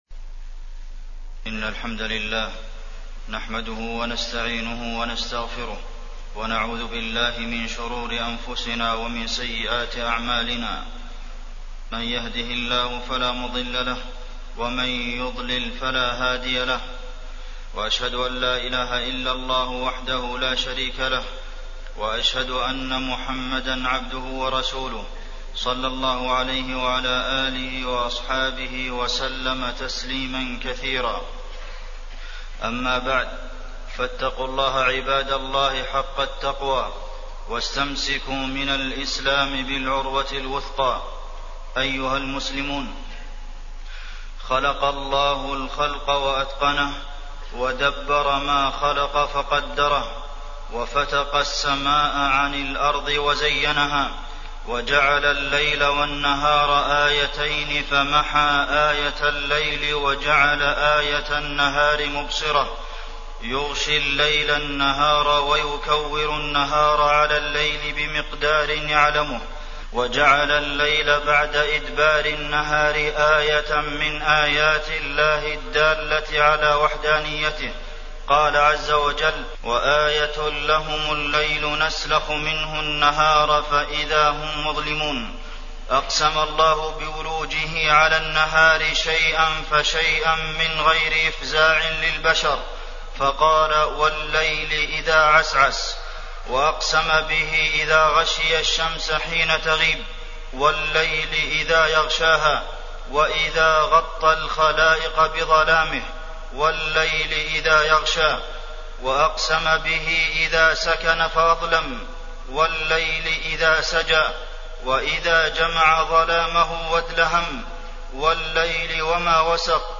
تاريخ النشر ٢١ ربيع الثاني ١٤٣٠ هـ المكان: المسجد النبوي الشيخ: فضيلة الشيخ د. عبدالمحسن بن محمد القاسم فضيلة الشيخ د. عبدالمحسن بن محمد القاسم آية تقلب الليل والنهار